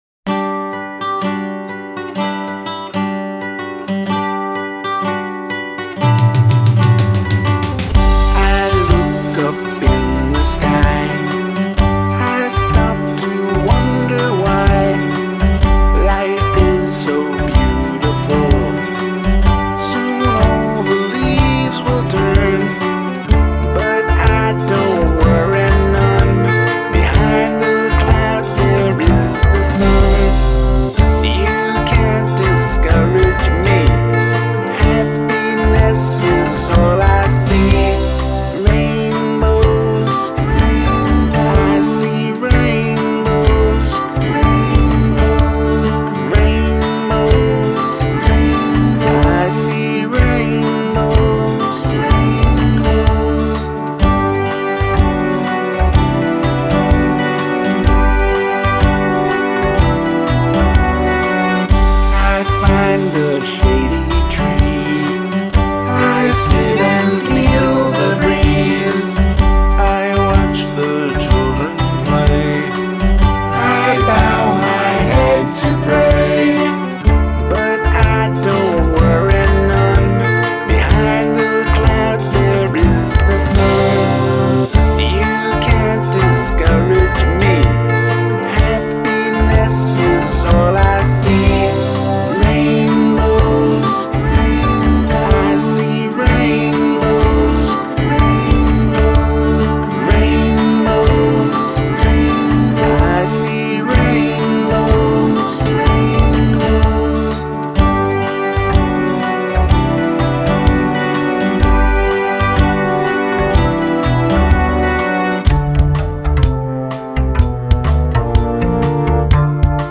A happy tune
The bass is very deep and full during the verses, and actually doesn't feel right to me for this song (great bass solo though ). The snare/sidestick/rimshot is buried. Otherwise it's a good mix.